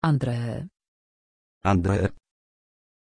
Pronunciación de Andrée
pronunciation-andrée-pl.mp3